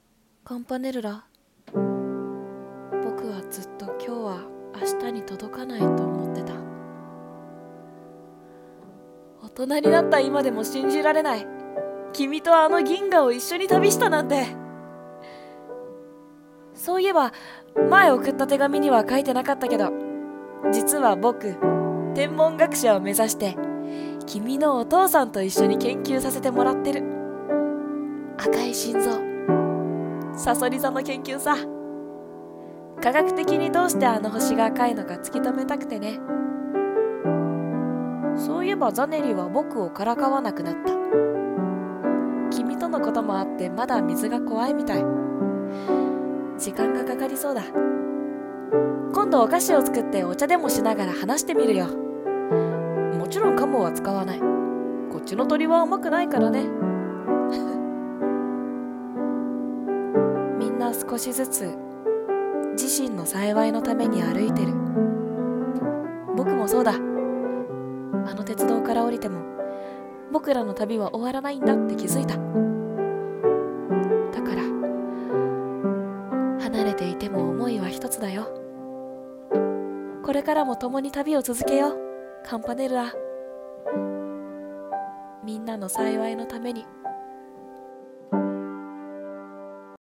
一人声劇【Dear C 】